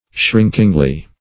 shrinkingly - definition of shrinkingly - synonyms, pronunciation, spelling from Free Dictionary Search Result for " shrinkingly" : The Collaborative International Dictionary of English v.0.48: Shrinkingly \Shrink"ing*ly\, adv. In a shrinking manner.